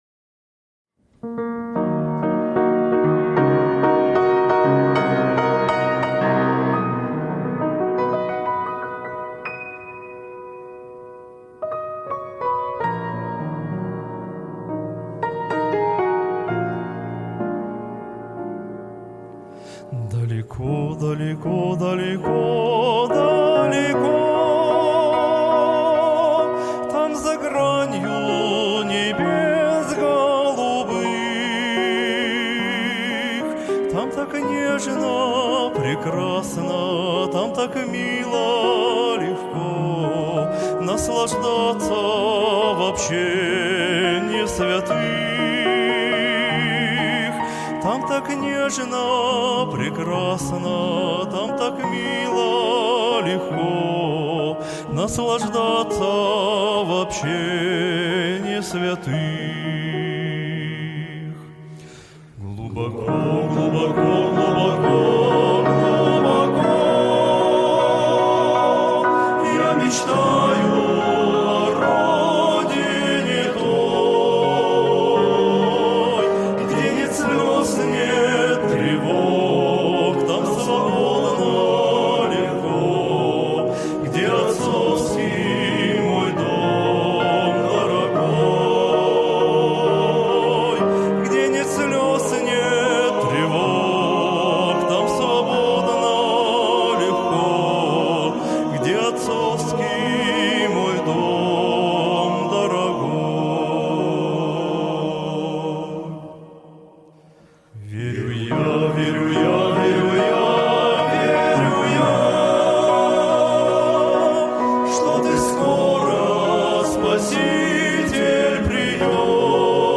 135 просмотров 153 прослушивания 9 скачиваний BPM: 75